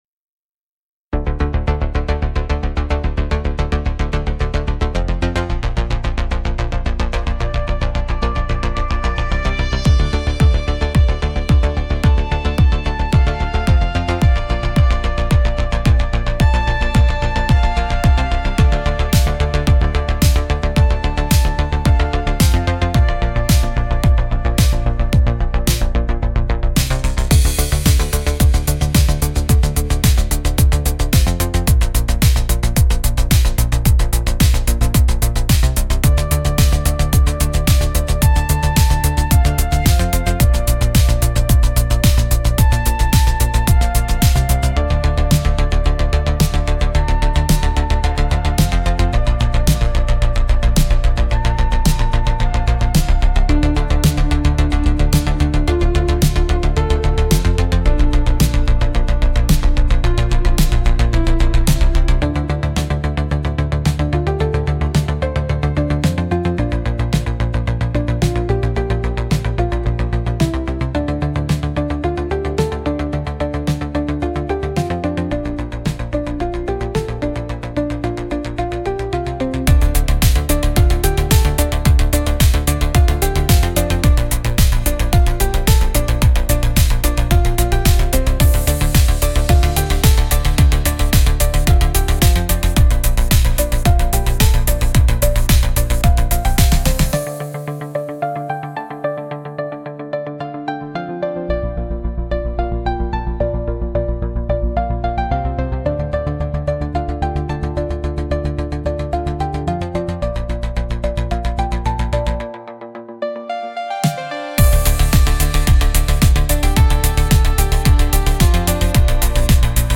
Instrumental -Real Liberty Media DOT xyz - 4 . mins